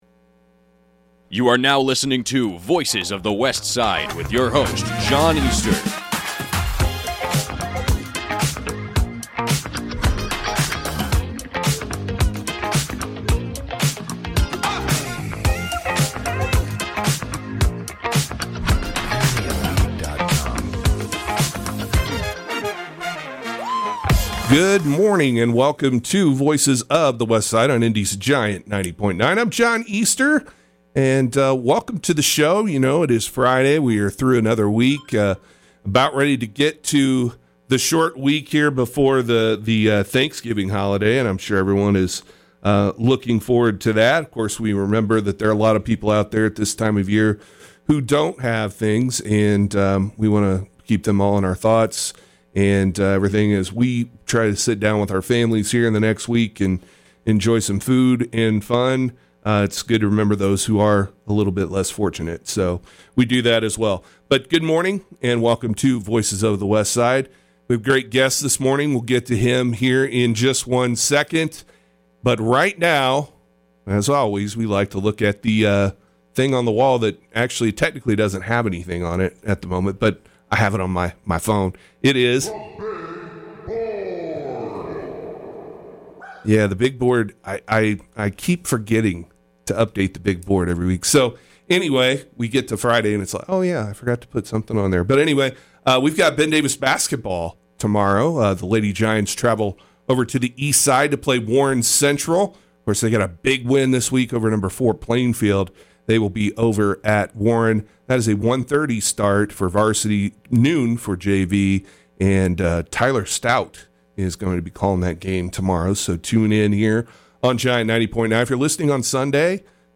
District 17 City-County Councillor Jared Evans joins the program to talk about a lot of the projects, proposals and things going on on the Westside of Indy.